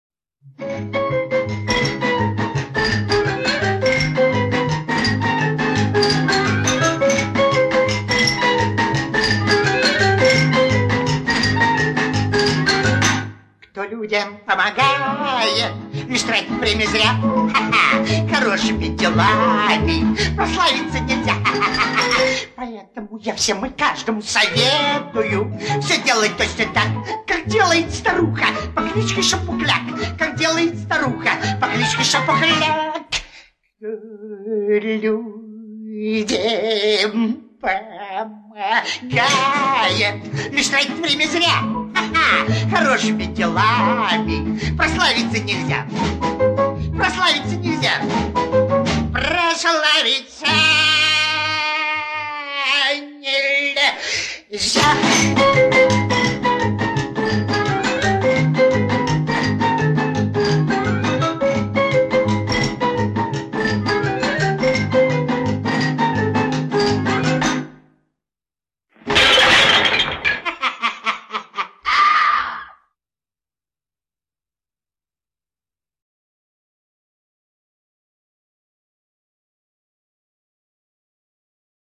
композиция из мультфильма